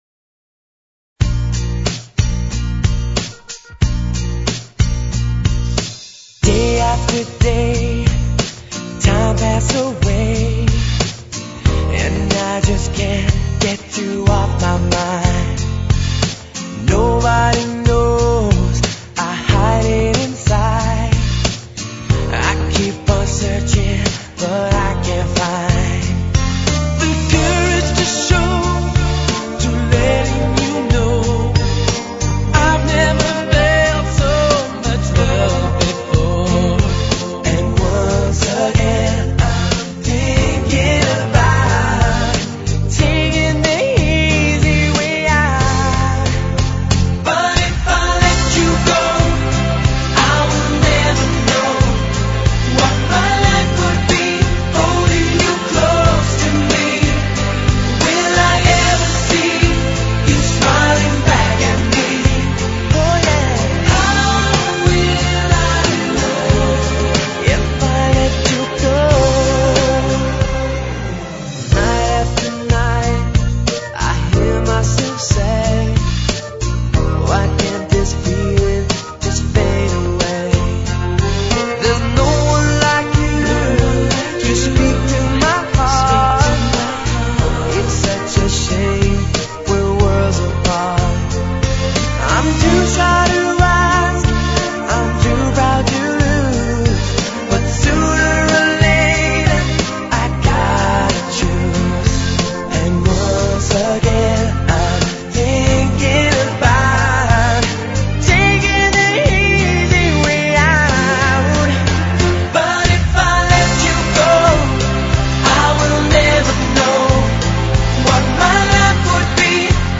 Genre Pop